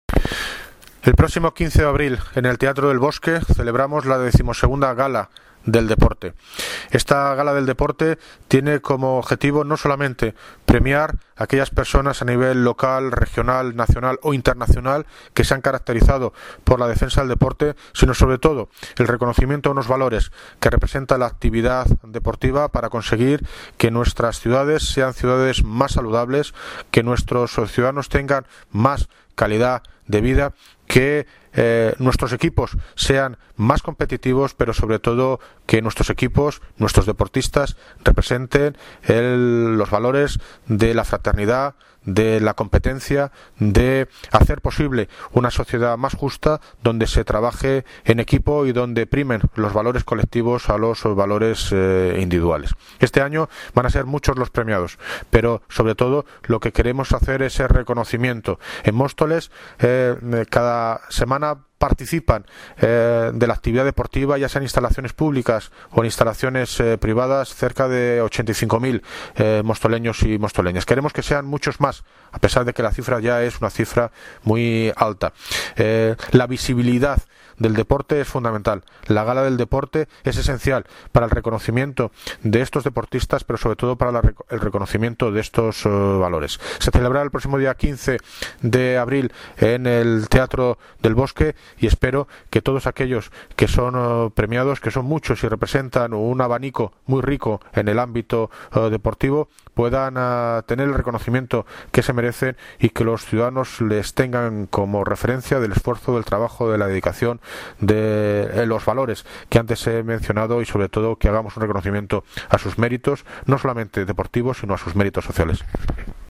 Audio - David Lucas (Alcalde de Móstoles) Sobre XII GALA DEL DEPORTE